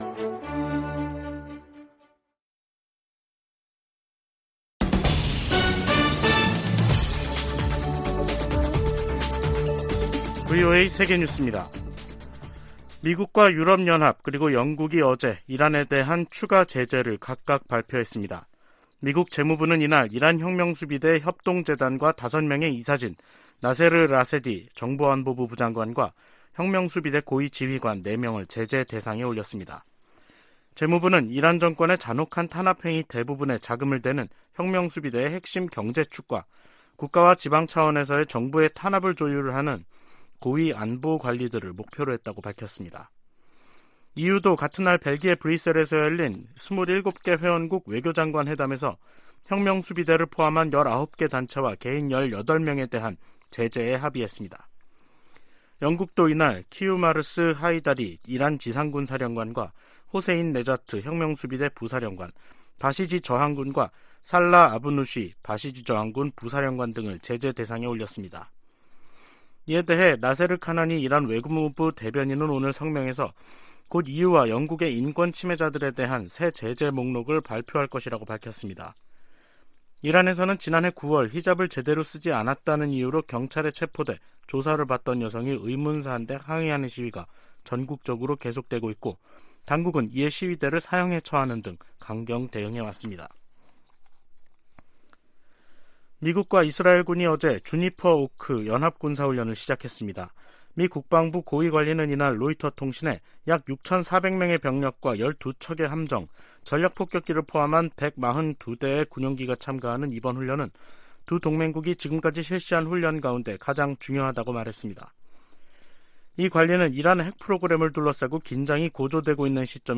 VOA 한국어 간판 뉴스 프로그램 '뉴스 투데이', 2023년 1월 24일 2부 방송입니다. 국무부는 북한과 러시아 용병 회사 간 무기거래와 관련해 한국 정부와 논의했다고 밝혔습니다. 북한에서 열병식 준비 정황이 계속 포착되는 가운데 평양 김일성 광장에도 대규모 인파가 집결했습니다.